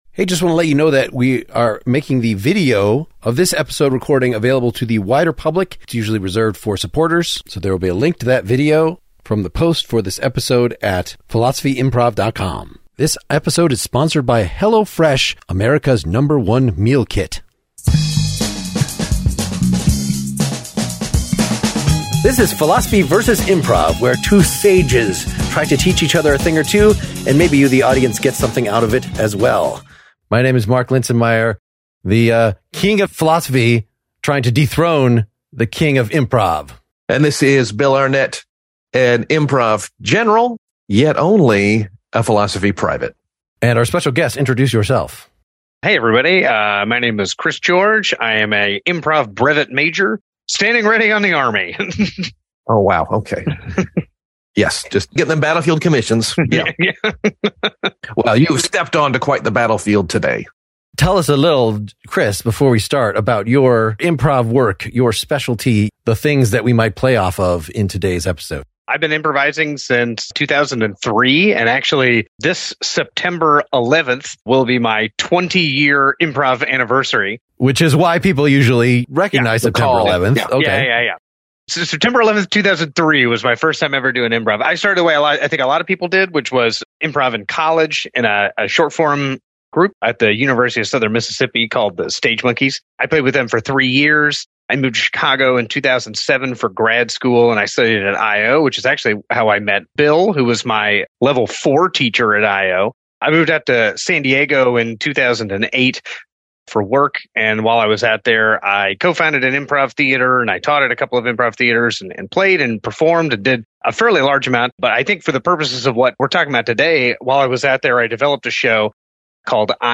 This spurs us for some reason to enact some scenes initiated by pantomime, i.e. silence.